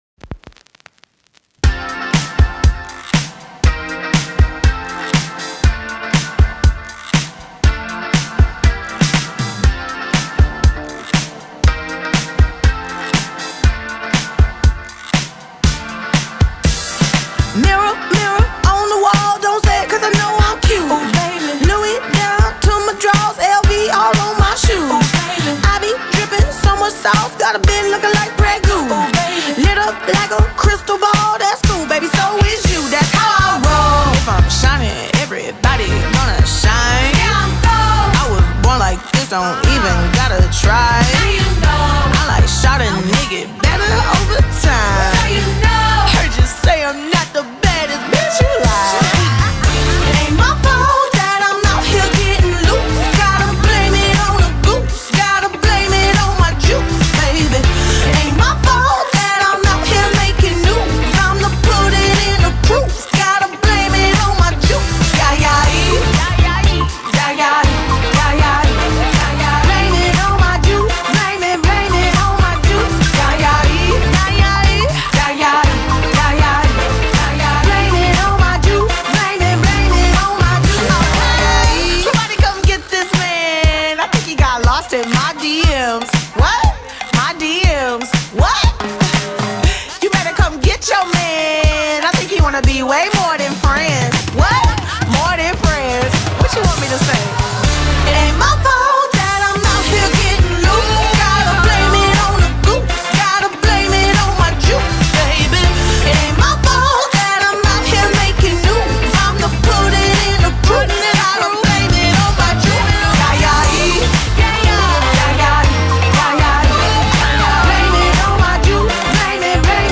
BPM120
Audio QualityCut From Video